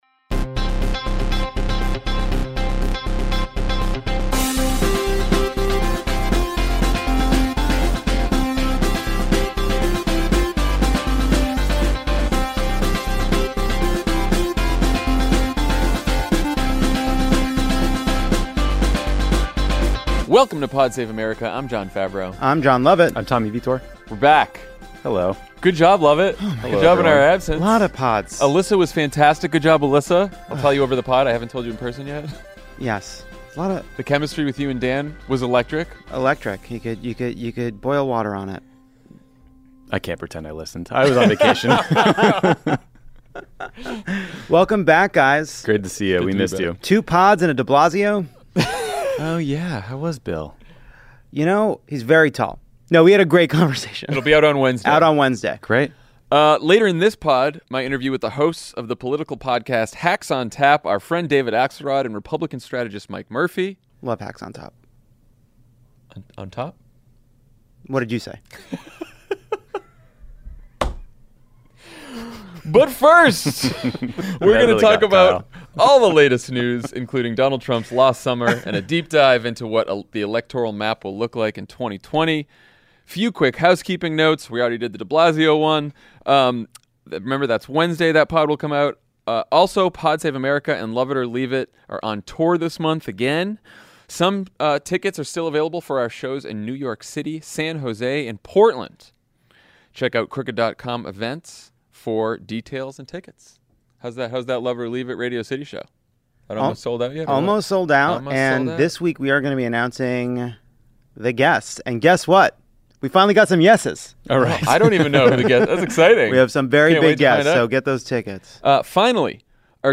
Trump caps off a lost summer by golfing and rage-tweeting through a hurricane, and a debate begins about which states are most important in 2020. Then political strategists David Axelrod and Mike Murphy, co-hosts of the Hacks On Tap podcast, talk to Jon F. about the Democratic primary and upcoming debate.